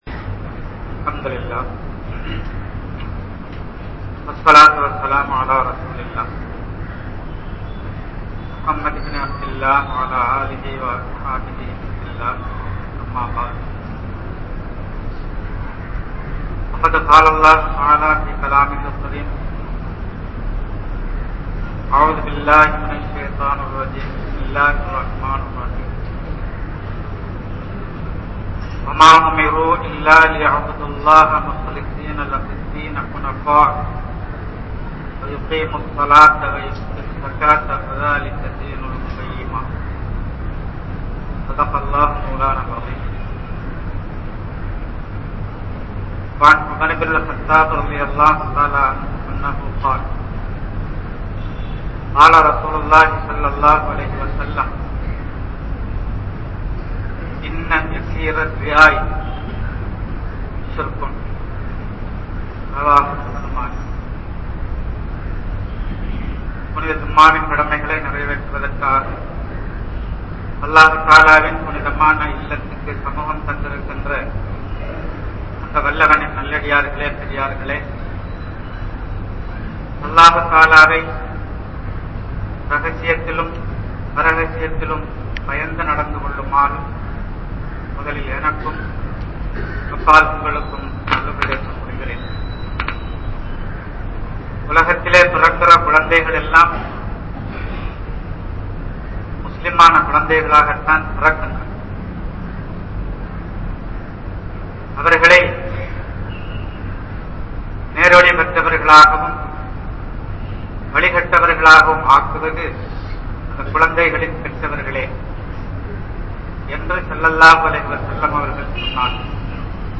Ihlaas | Audio Bayans | All Ceylon Muslim Youth Community | Addalaichenai